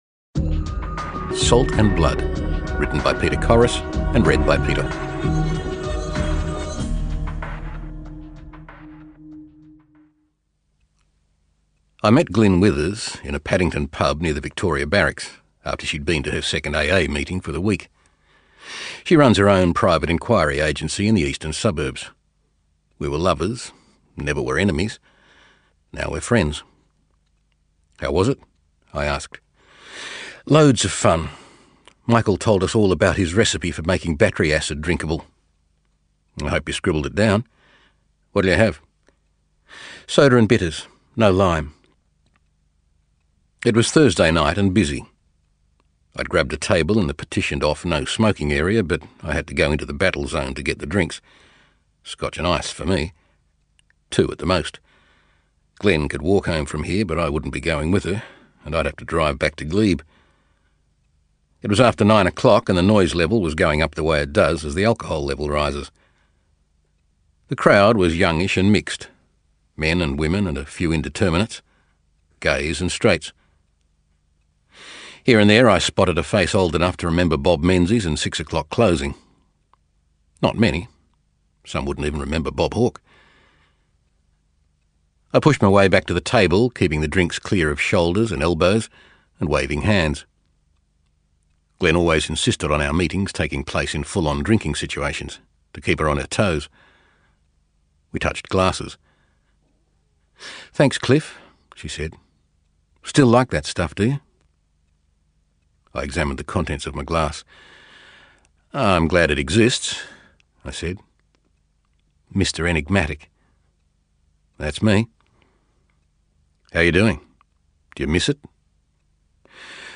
Audiobooks
Rode NT-1A Microphone, Focusrite interface.
BaritoneDeepLow